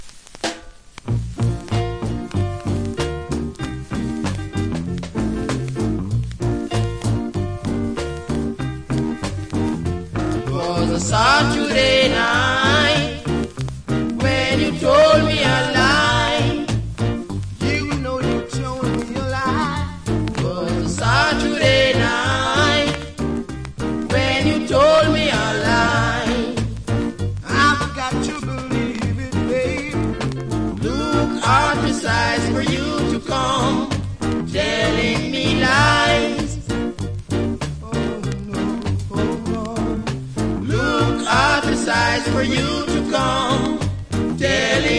Sweet Rock Steady Vocal.